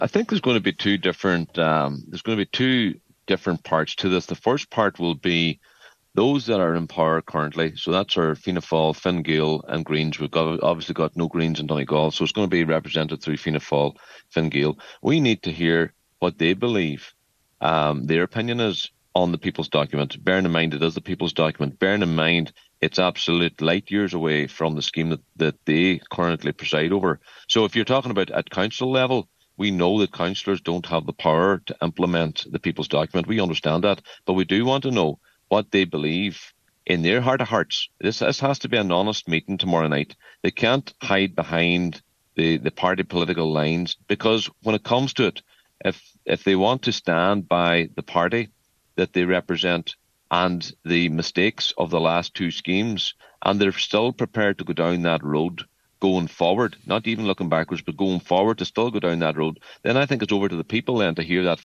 Defective block campaigner